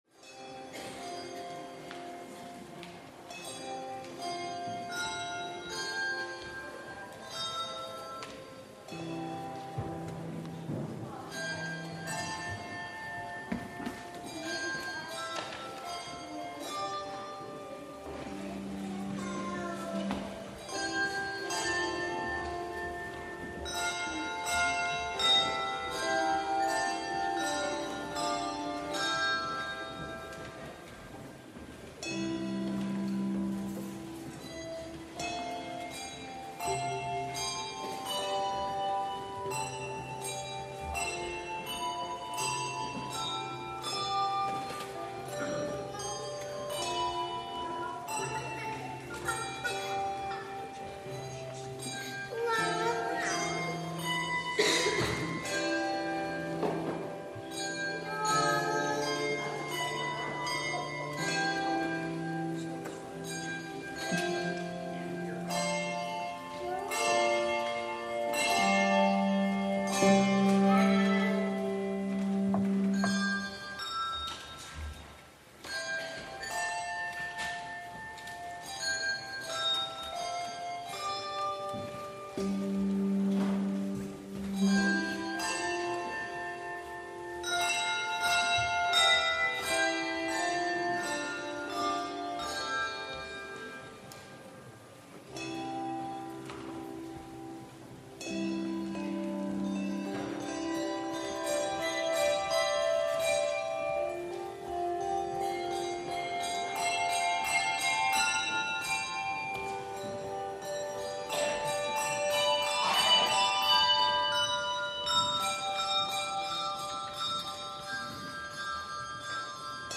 Central-Church-5-3-20-worship.mp3